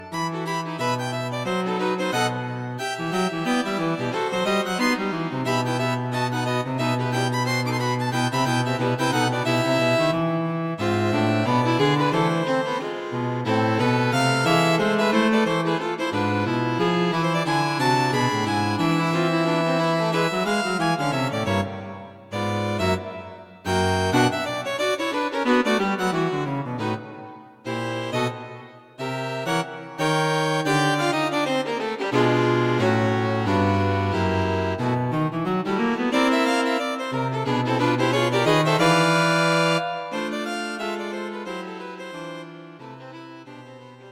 String Quartet for Concert performance
A fast, thorny piece.
Written for string quartet.